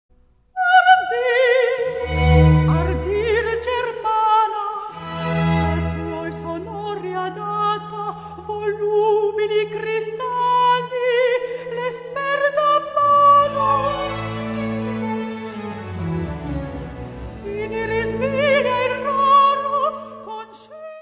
seraphim, armonica, cristal baschet.
: stereo; 12 cm
Music Category/Genre:  Classical Music